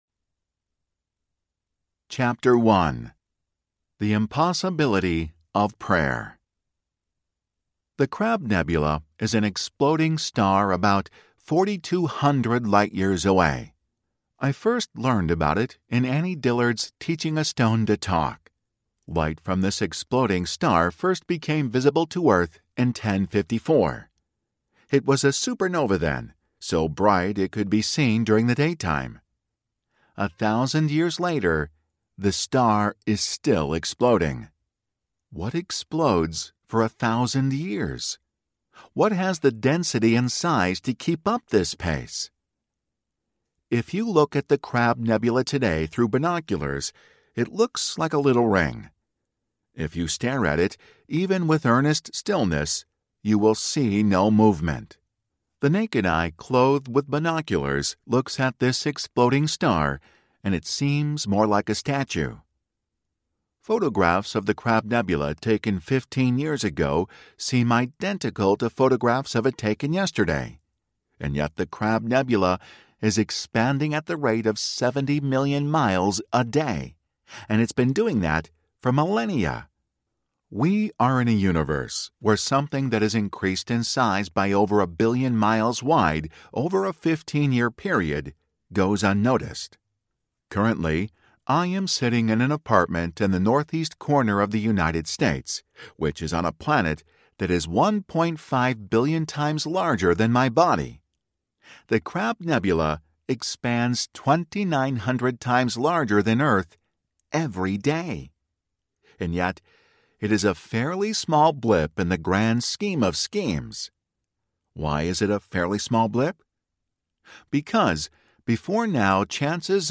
The Possibility of Prayer Audiobook
6.4 Hrs. – Unabridged